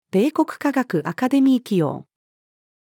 米国科学アカデミー紀要-female.mp3